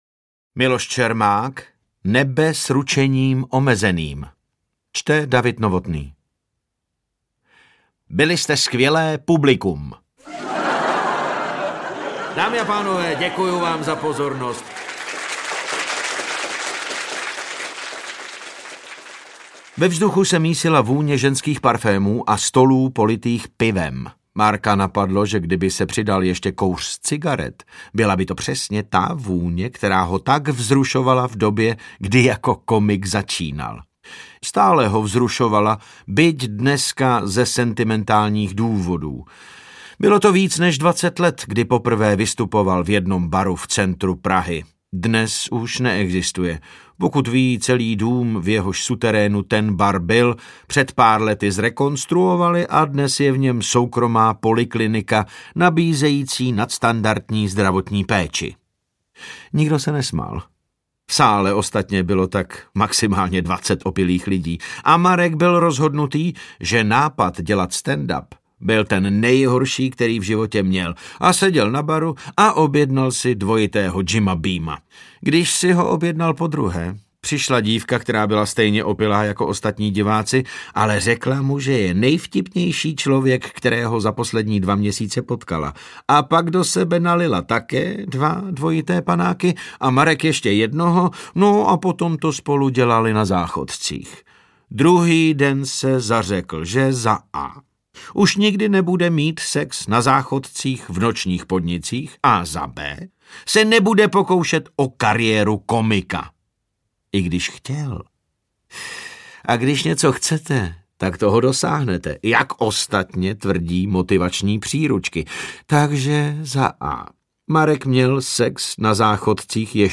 Audiobook
Read: David Novotný